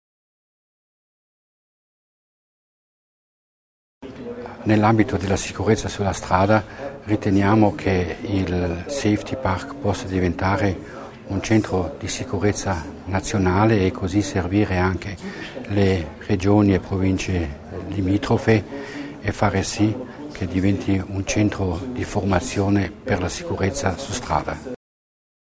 L'Assessore Mussner spiega la collaborazione tra ACI e Safety Park